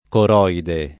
coroide [ kor 0 ide ]